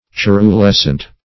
Search Result for " cerulescent" : The Collaborative International Dictionary of English v.0.48: Cerulescent \Ce`ru*les"cent\, a. [L. caeruleus sky-blue + -escent.]